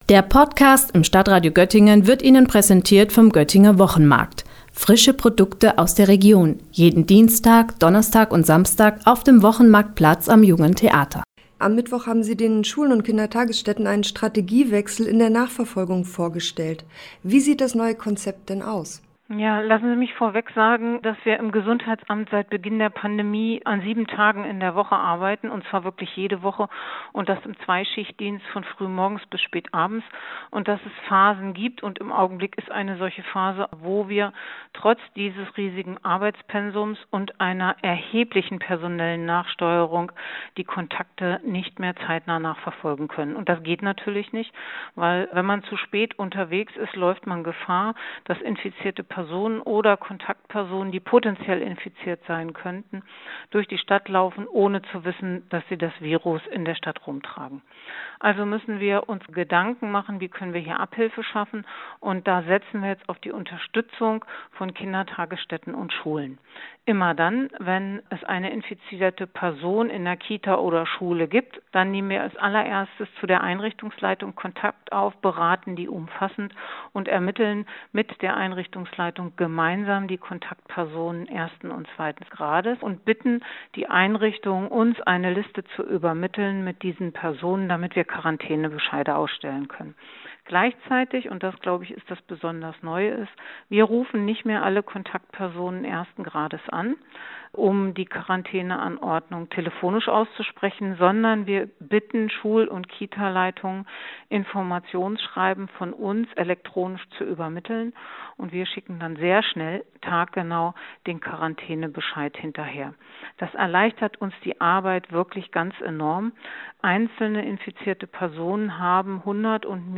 Dabei sollen künftig die Kitas und Schulen stärker in die Verantwortung für die Kontaktermittlung einbezogen werden. Wie das aussehen soll, erklärt die Leiterin des Stabs für außergewöhnliche Ereignisse und Dezernentin für Kultur und Soziales Petra Broistedt.